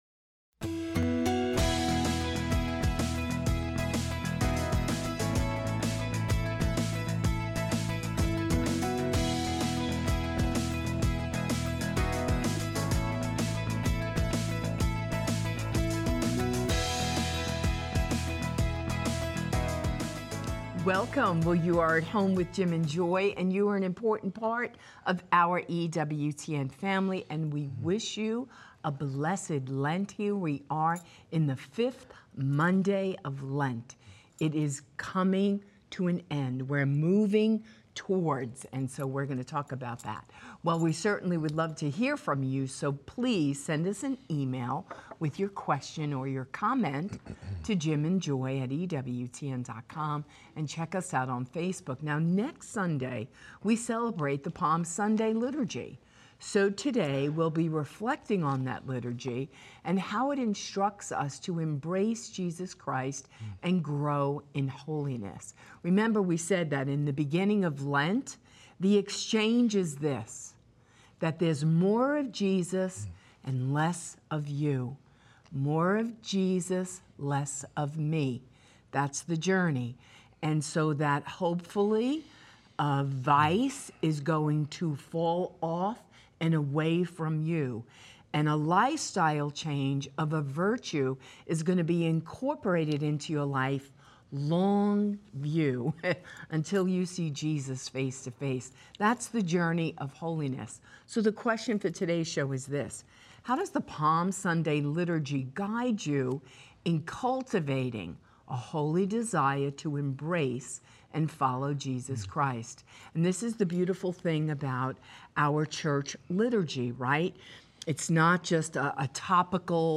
Call-in Show
Viewer Questions